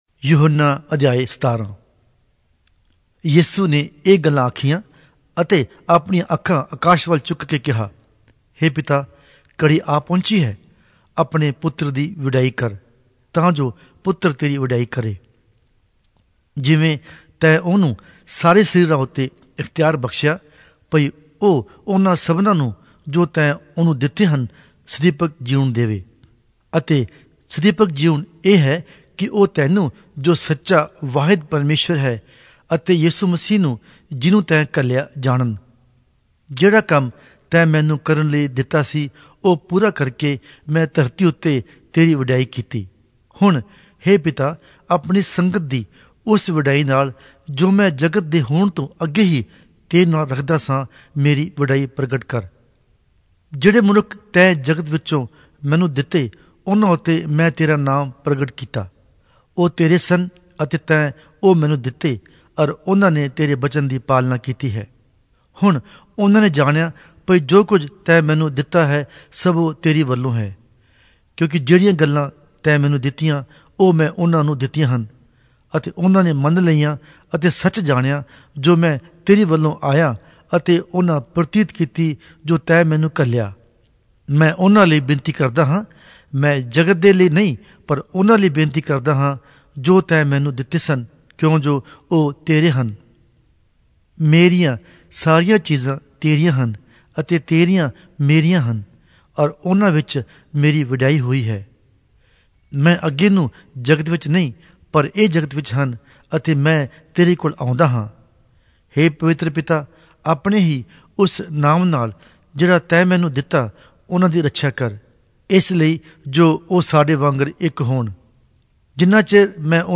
Punjabi Audio Bible - John 15 in Ervte bible version